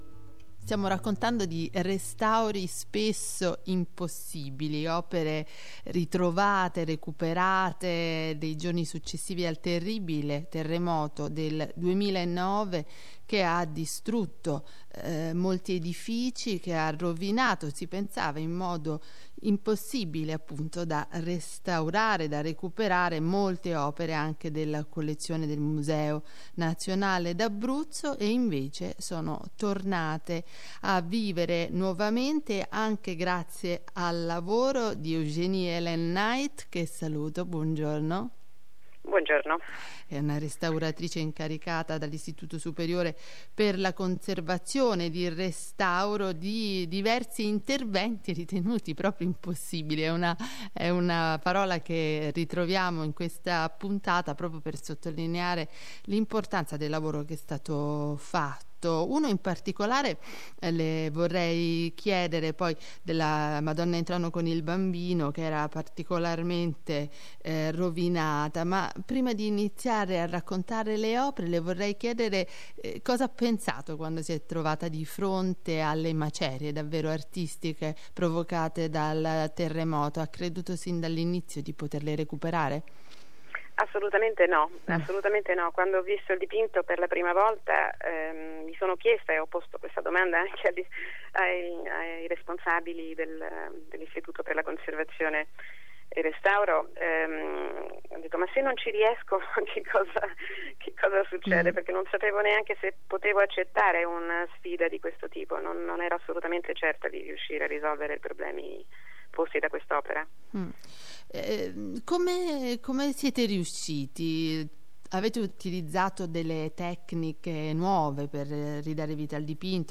Intervista nell’ambito del convegno Getty/La Venaria Reale 2022 “Interventi strutturali per dipinti su tela con adesivi acquosi”